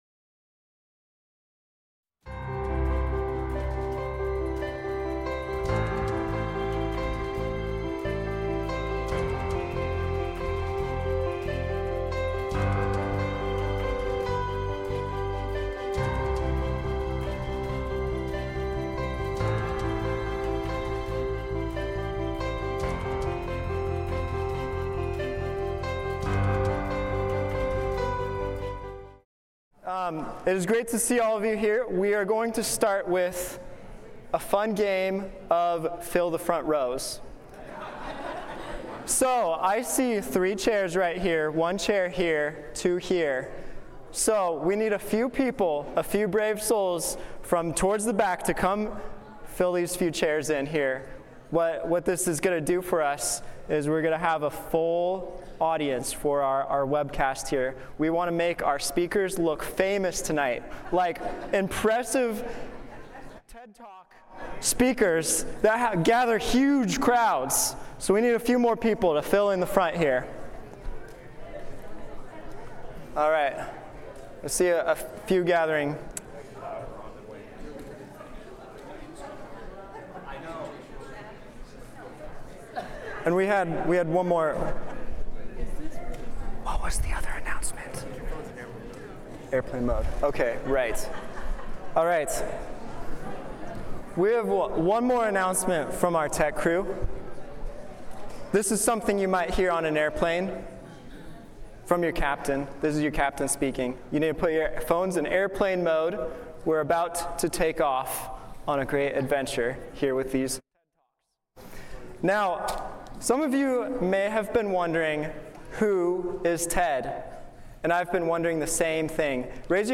This sermon was given at the Spokane Valley, Washington 2023 Feast site.